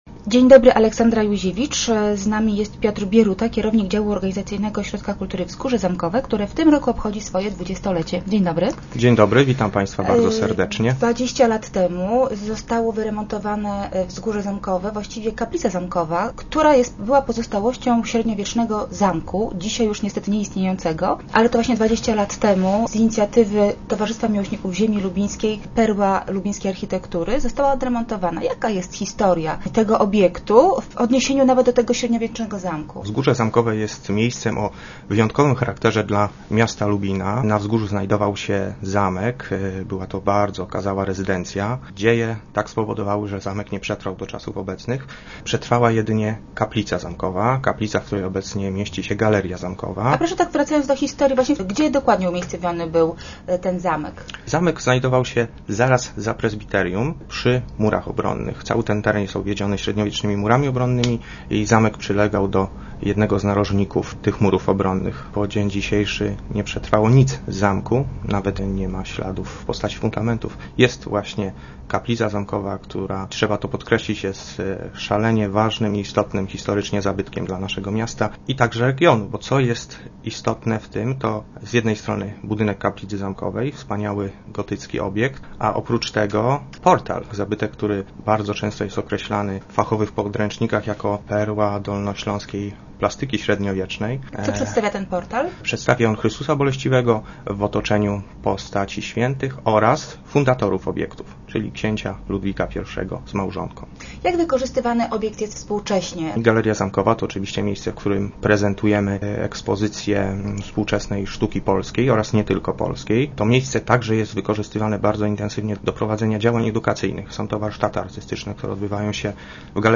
Start arrow Rozmowy Elki arrow Jubileusz Wzgórza